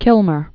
(kĭlmər), (Alfred) Joyce 1886-1918.